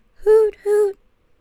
HOOTHOOT.wav